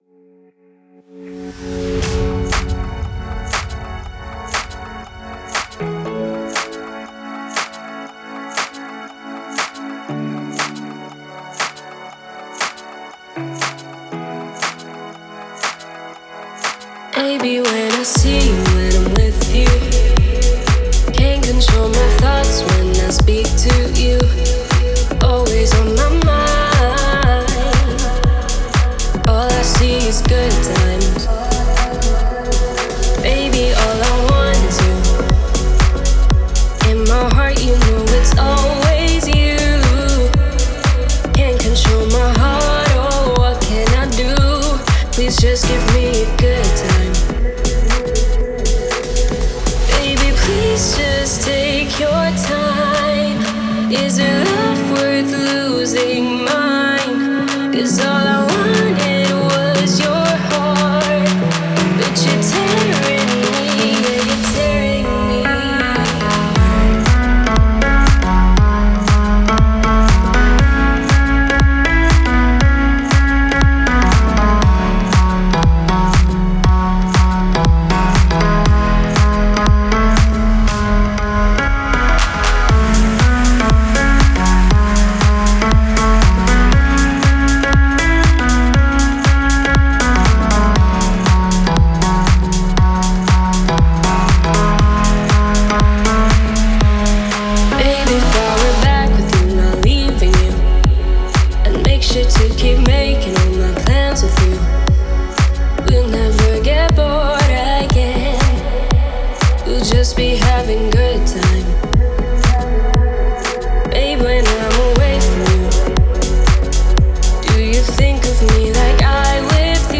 это энергичная композиция в жанре афро-поп